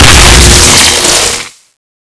green_explode.wav